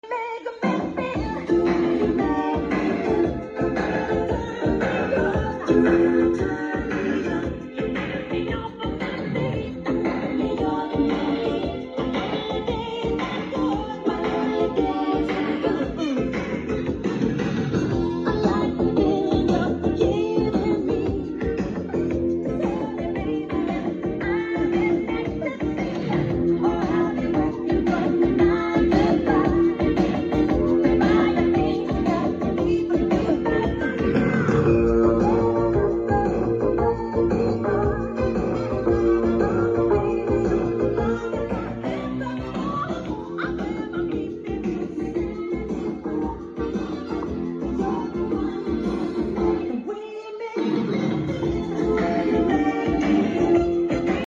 Home theater Samsung 3d blu-ray